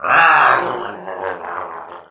Wario growls underwater in Mario Kart Wii.
Wario_(Drown_3)_Mario_Kart_Wii.oga